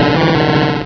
pokeemerald / sound / direct_sound_samples / cries / politoed.aif